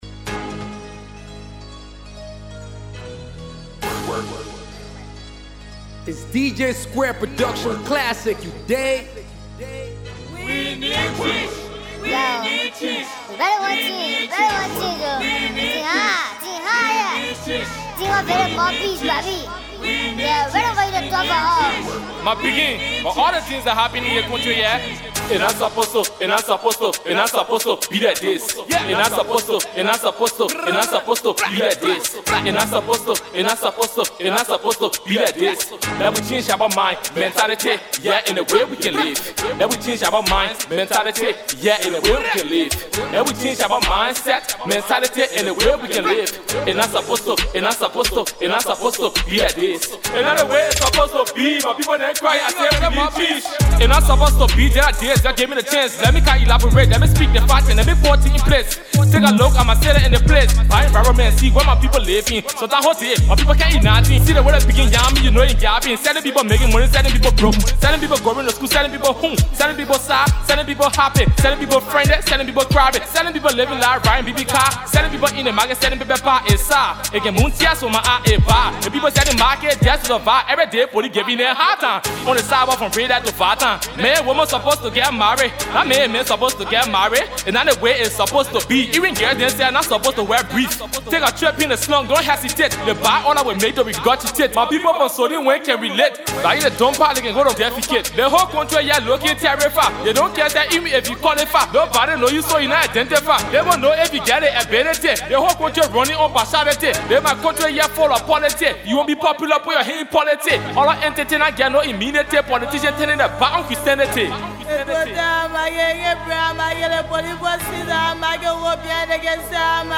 / Hip-Co, Hip-Hop / By